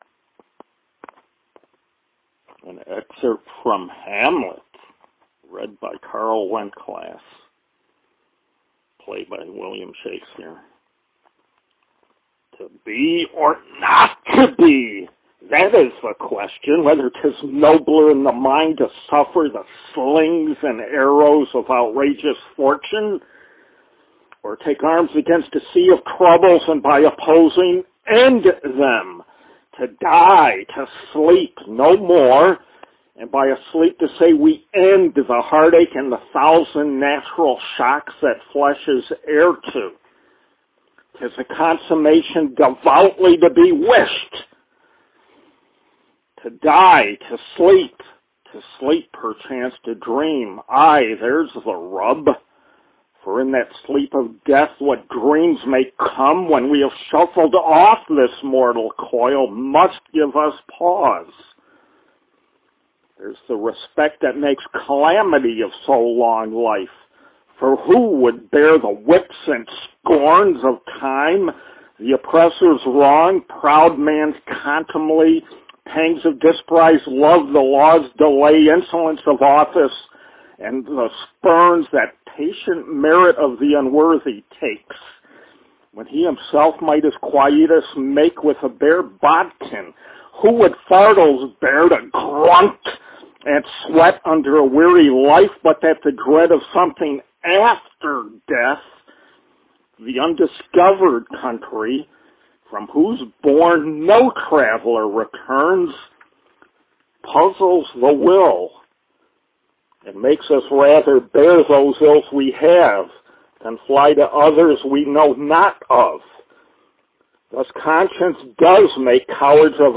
A HAMLET SOLILOQUY
In tribute then to the man from Stratford, I present my own rough version of the Bard’s most famous soliloquy, the “To be or not to be” speech– from Hamlet!